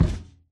Sound / Minecraft / mob / irongolem / walk1.ogg
walk1.ogg